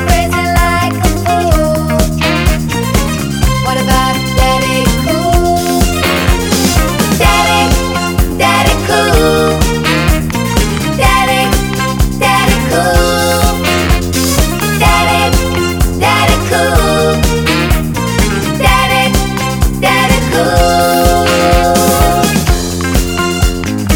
For Solo Female Disco 3:26 Buy £1.50